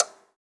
clock_tick_04.wav